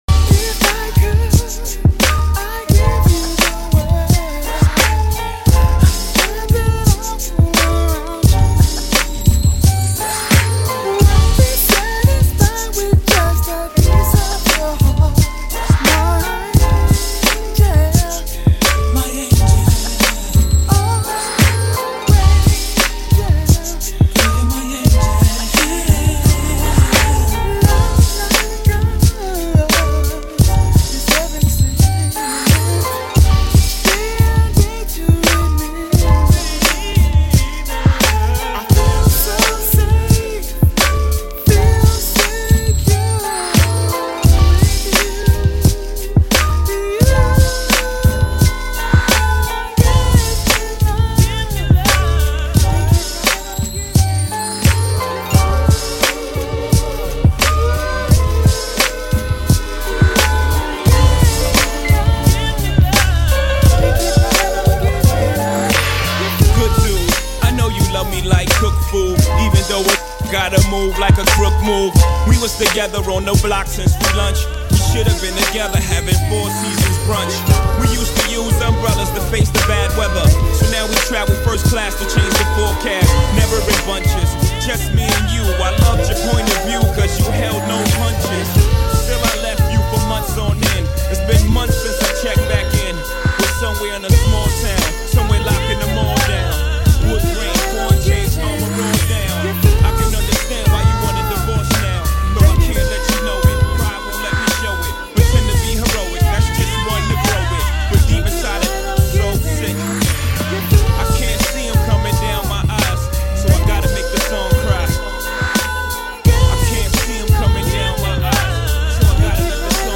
Old School RnB